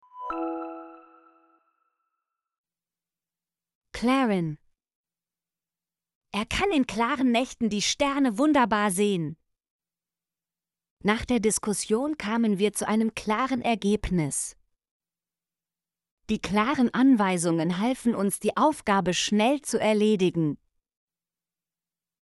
klaren - Example Sentences & Pronunciation, German Frequency List